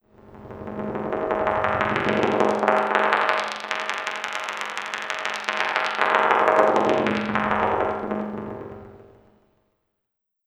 Lo Fi Event No 2.wav